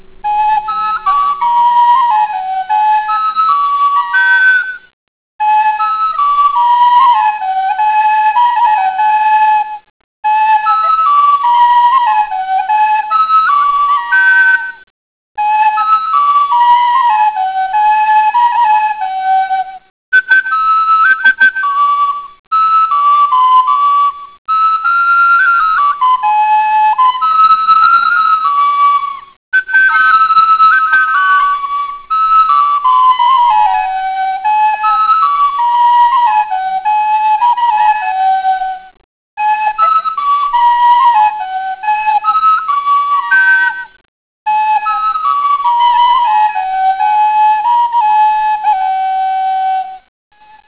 חלילים:אני
הקלטתי בבית במיקרופון..
נשמע כמו שיר רועים.
מעט צורם.